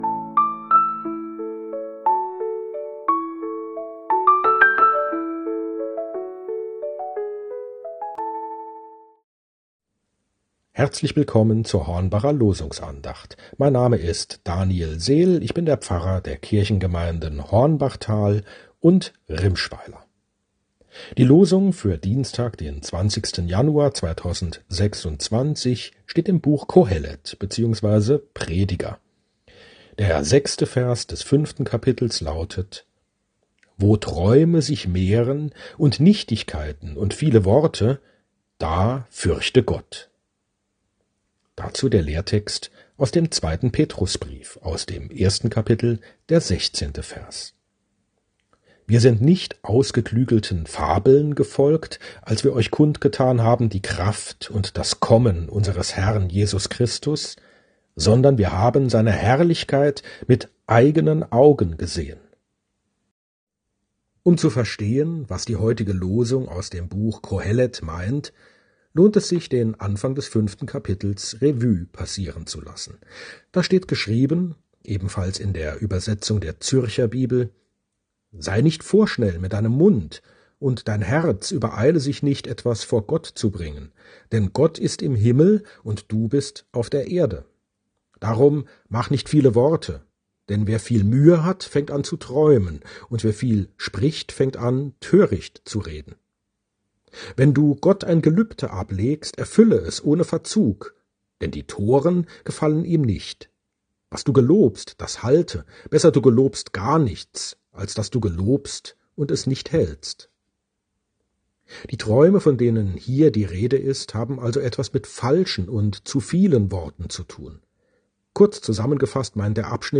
Losungsandacht für Dienstag, 20.01.2026
Losungsandachten